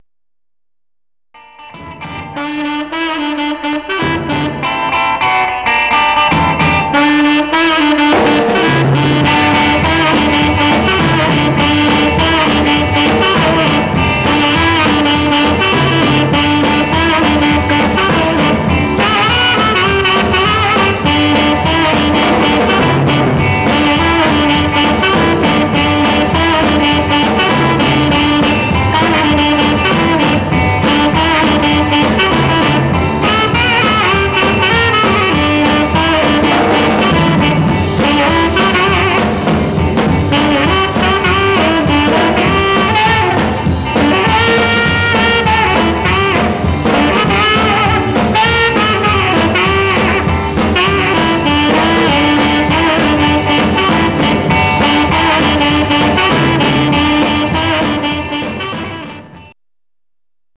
Condition Surface noise/wear Stereo/mono Mono
R & R Instrumental